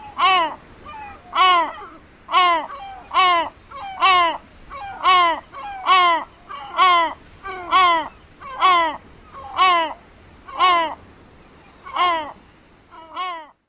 大黑背鸥叫声 “咿哦”声